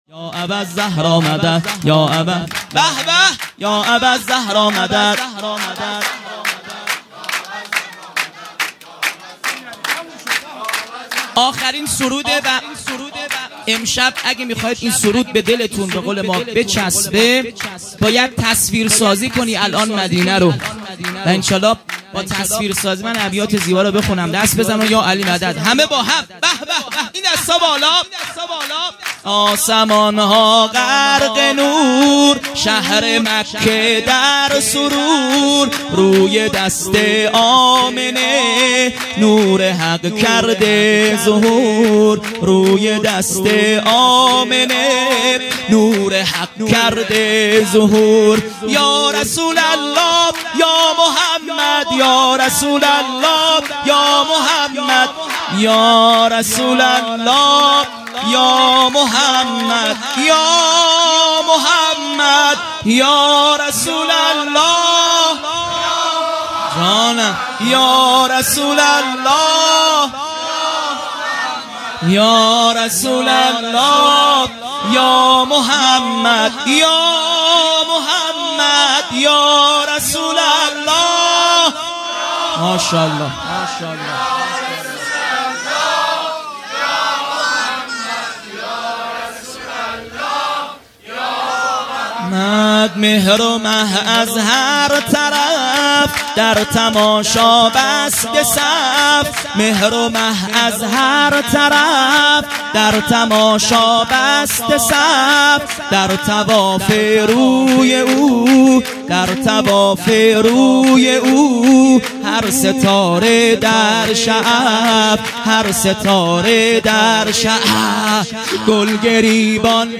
سرود ۳ |آسمان ها غرق نور مداح